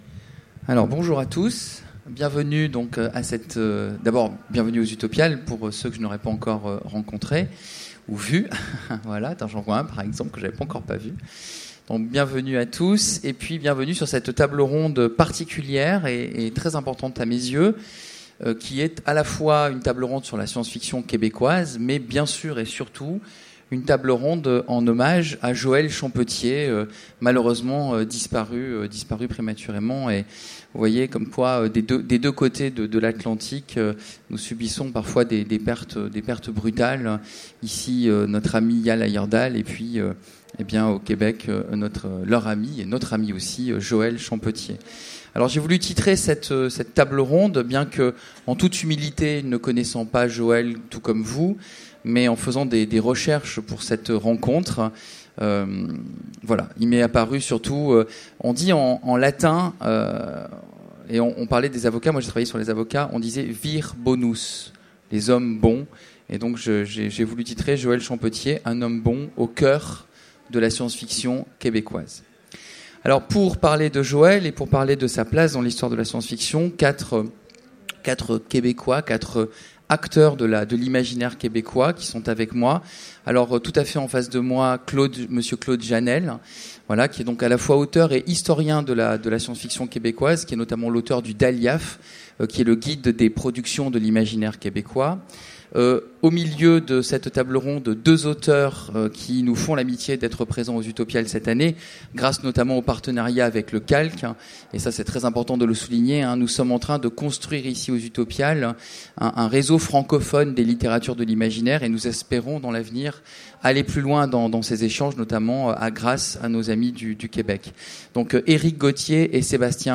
Utopiales 2015 : Conférence Celui qui faisait battre le cœur « dur » de la SF québécoise - ActuSF - Site sur l'actualité de l'imaginaire